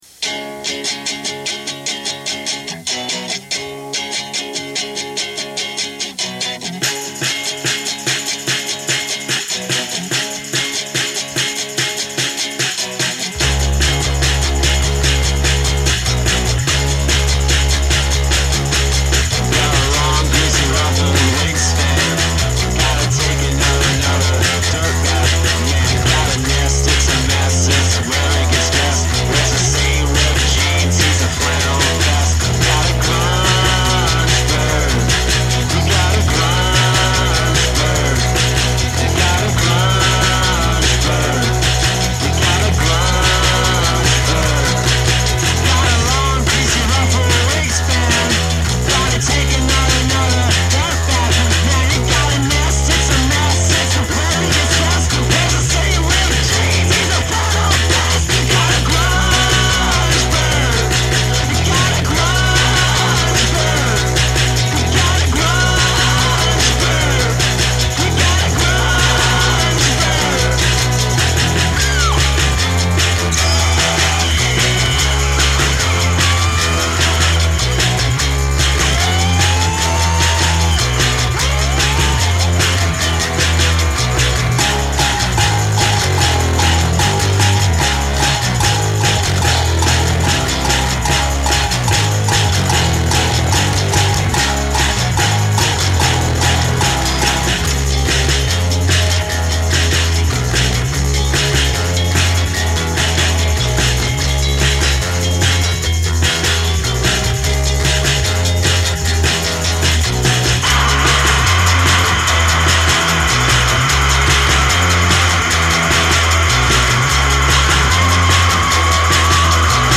lo-fi pop